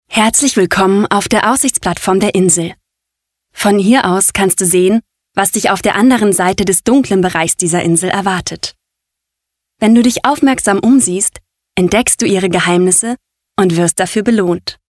Authentische, von jung frisch frech über verträumt sinnlich bis dynamisch seriöse Stimme.
Sprechprobe: eLearning (Muttersprache):
Female voice over artist German/English. Authentic voice, from young and fresh to sleepy sensual and dynamic respectable.